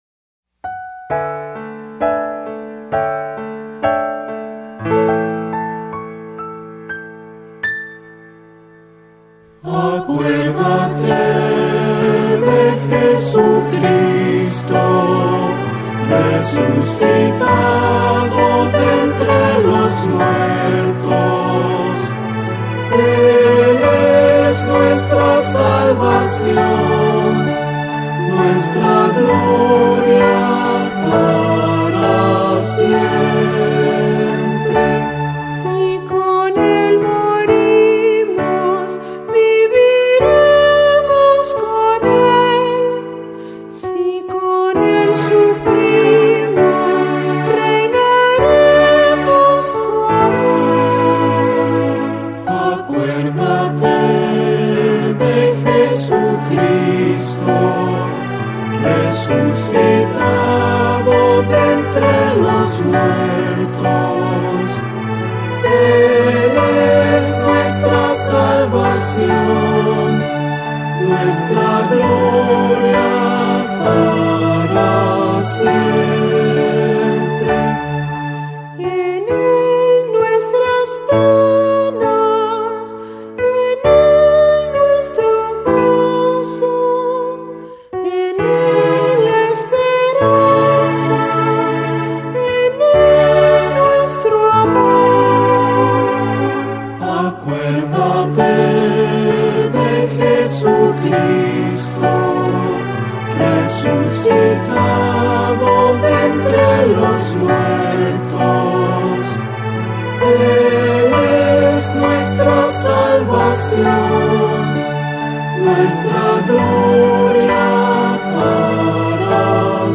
Cantos Litúrgicos